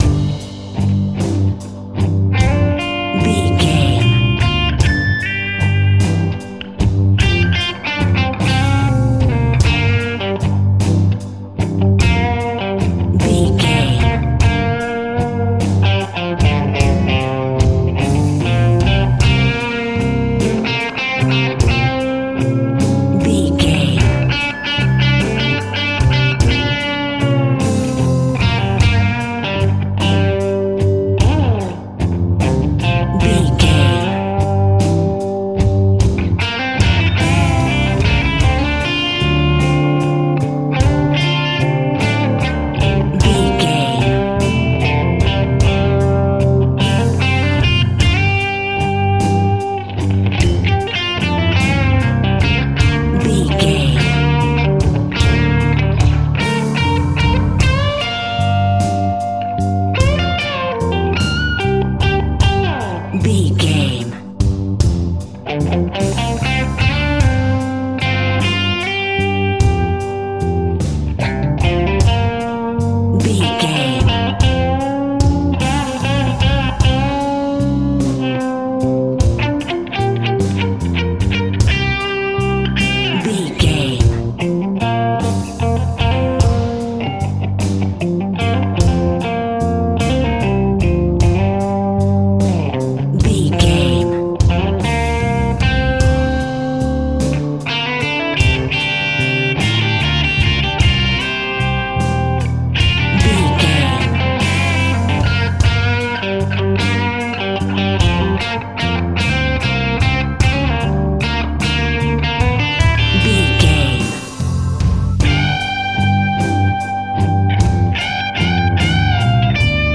Uplifting
Aeolian/Minor
SEAMLESS LOOPING?
DOES THIS CLIP CONTAINS LYRICS OR HUMAN VOICE?
electric guitar
bass guitar
drums